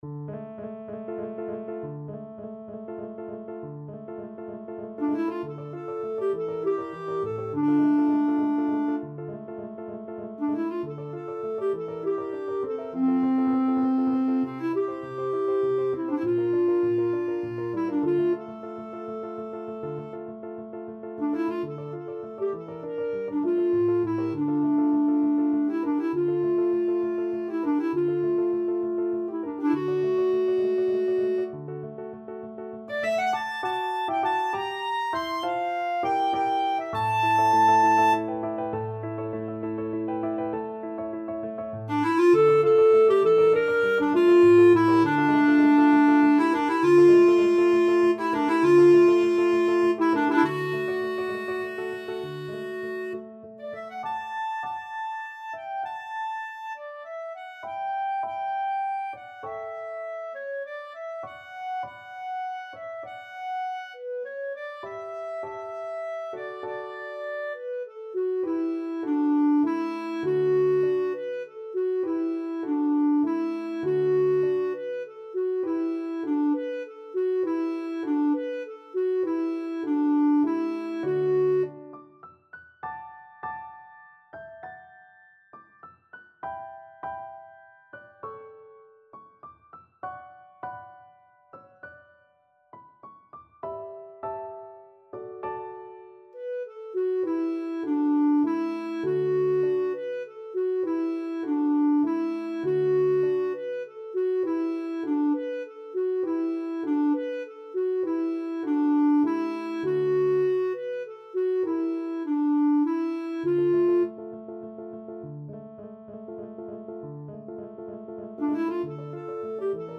6/8 (View more 6/8 Music)
Andante, quasi Allegro =100 (View more music marked Allegro)
Classical (View more Classical Clarinet Music)